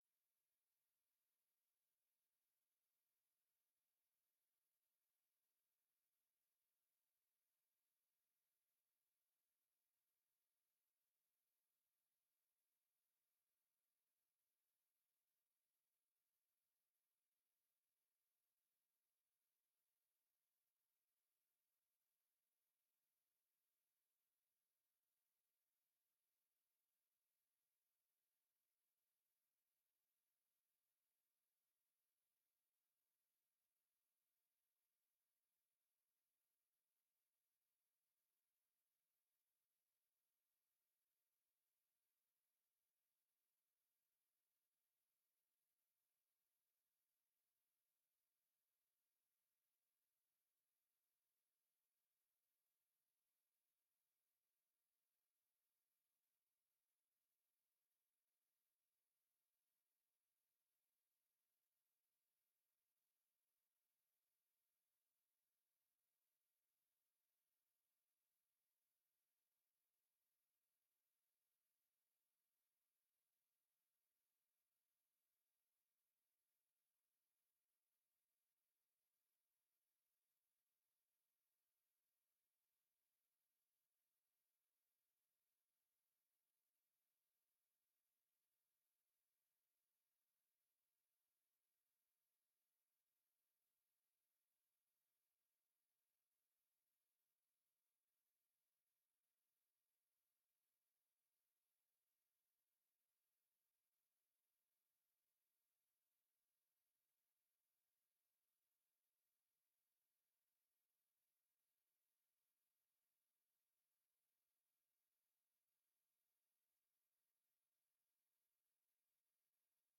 Raadsvergadering 06 februari 2025 20:00:00, Gemeente Ouder-Amstel
Download de volledige audio van deze vergadering